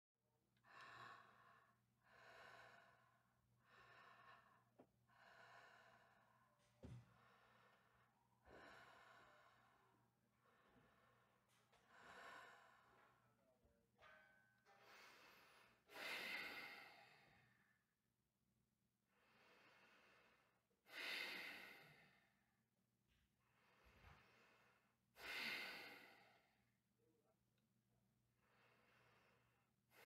دانلود صدای نفس کشیدن دختر 1 از ساعد نیوز با لینک مستقیم و کیفیت بالا
جلوه های صوتی